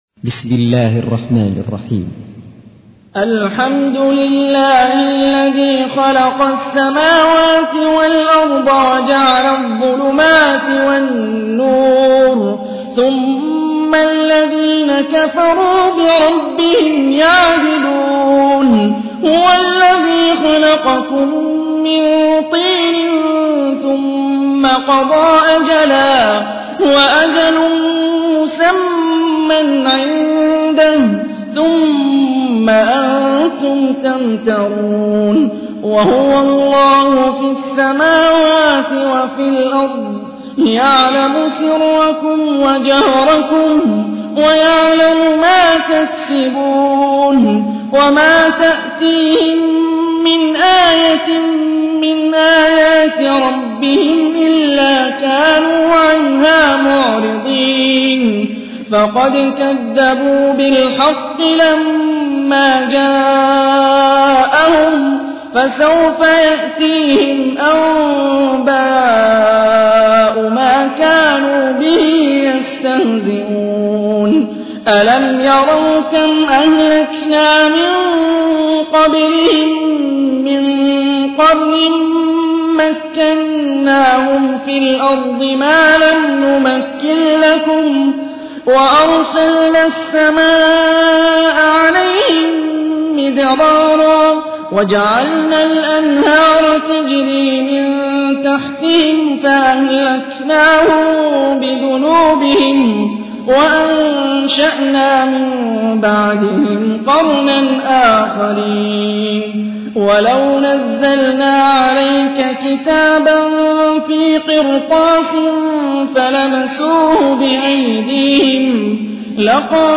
تحميل : 6. سورة الأنعام / القارئ هاني الرفاعي / القرآن الكريم / موقع يا حسين